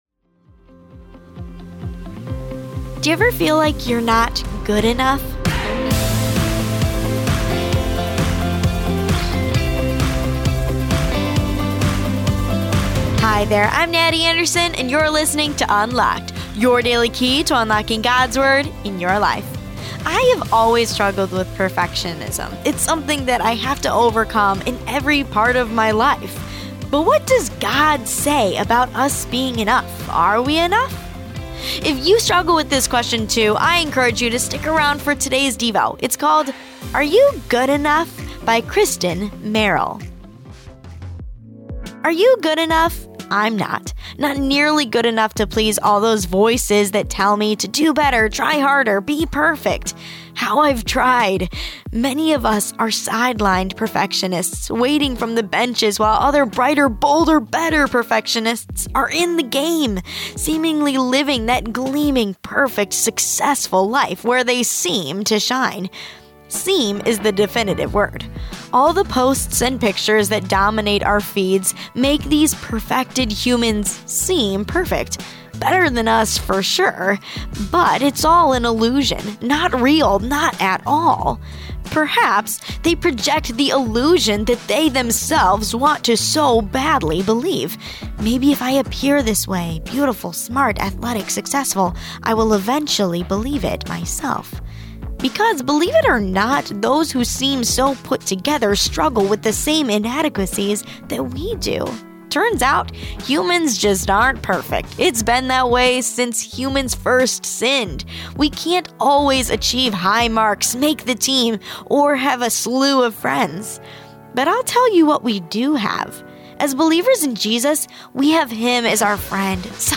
With daily devotions read by our hosts